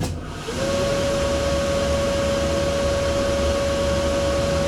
VENTILATR1-S.WAV